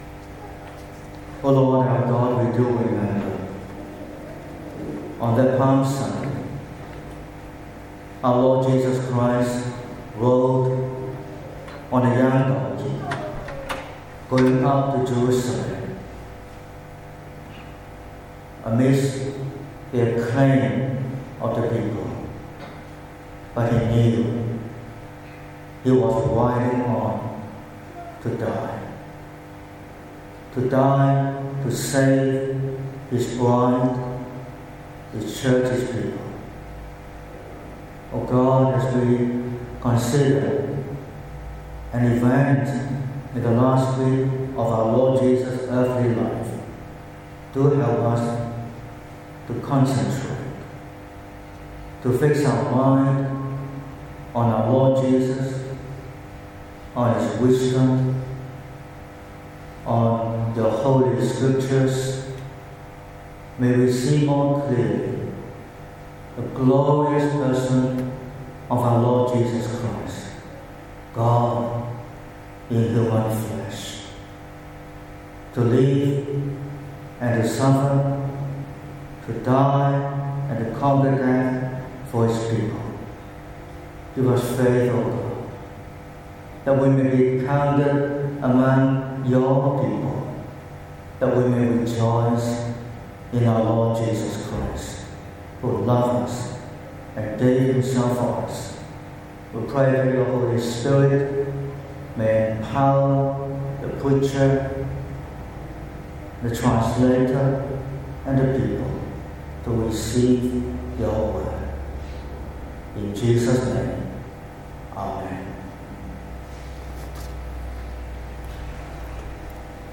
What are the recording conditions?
29/03/2026 – Morning Service: Son and Lord